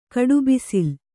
♪ kaḍubisil